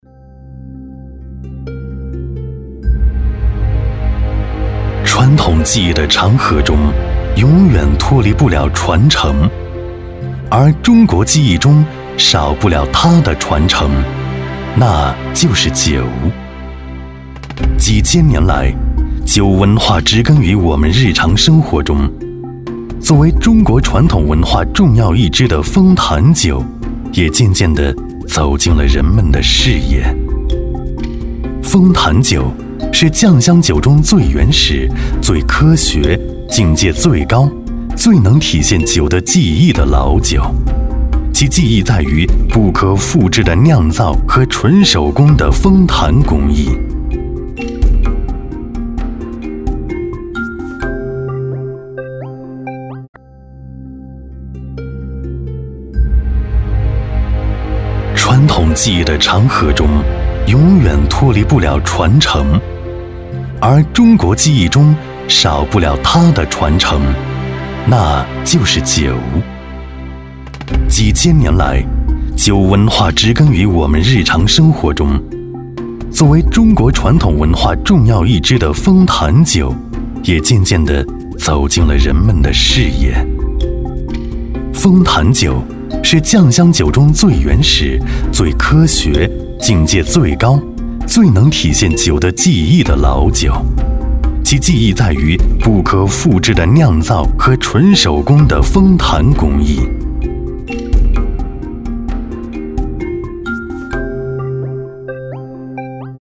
男S356 国语 男声 专题片-酒-大气、沧桑 大气浑厚磁性|沉稳